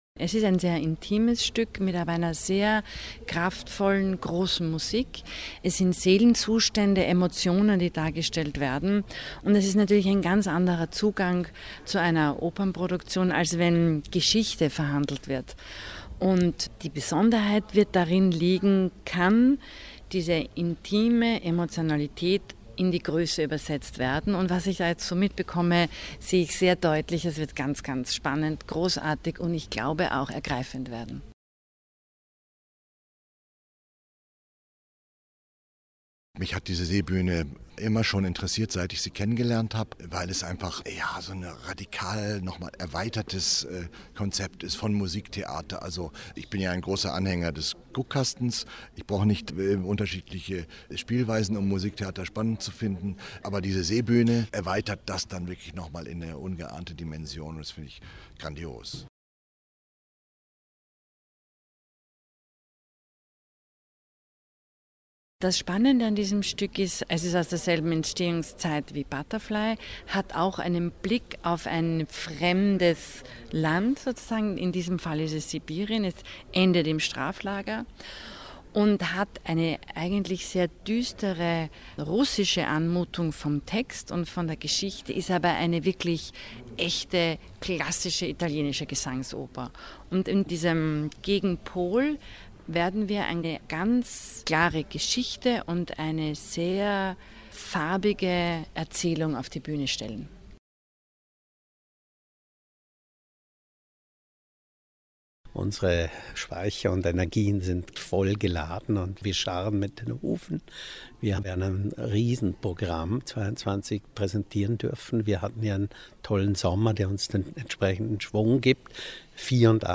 Festspielprogramm 2022 O-Töne feature